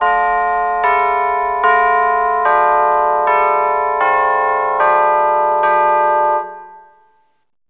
1 channel
tubbells.wav